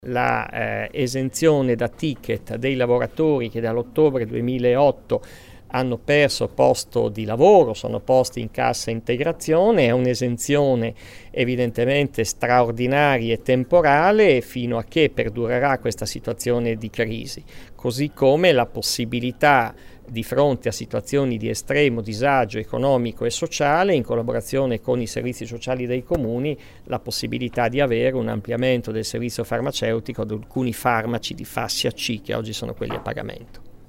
Ascolta l’assessore alla Sanità Giovanni Bissoni